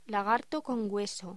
Locución: Lagarto con hueso
voz